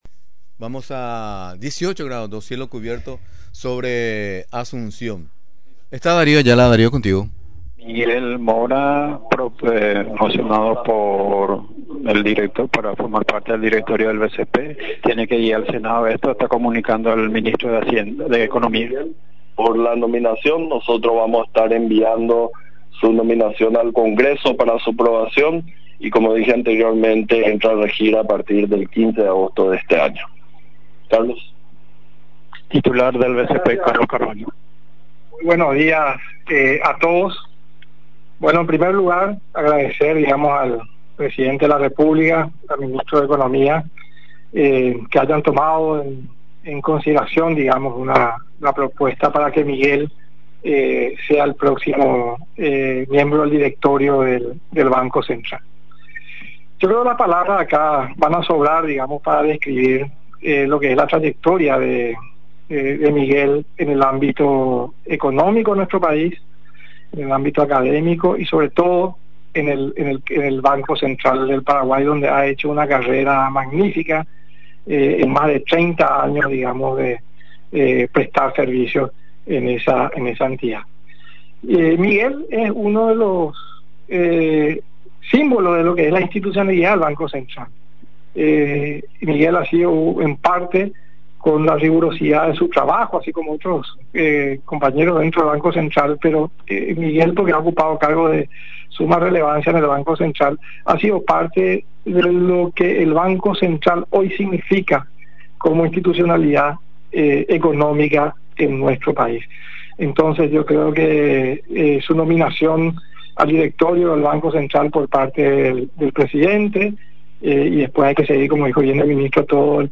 El encargado de dar el anuncio fue el ministro de Economía y Finanzas, Carlos Fernández Valdovinos, durante una conferencia de prensa que se realizó este lunes en Mburuvicha Róga.